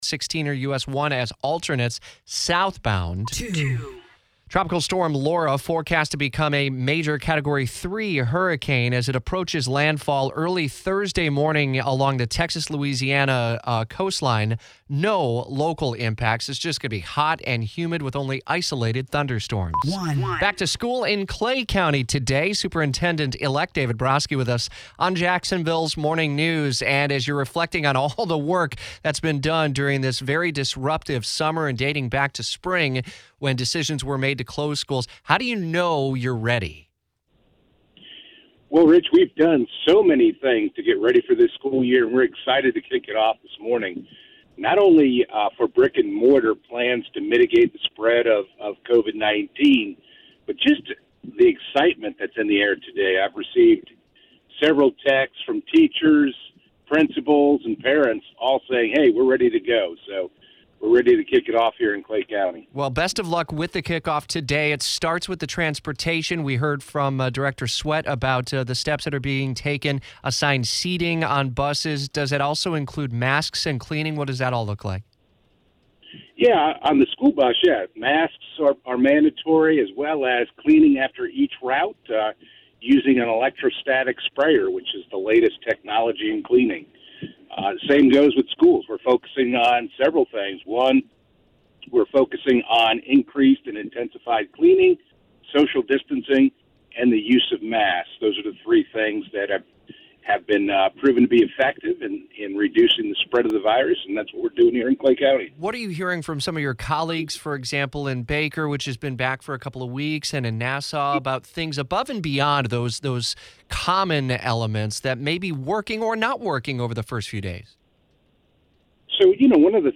Clay Superintendent David Broskie on Jacksonville’s Morning News ahead of the first day of school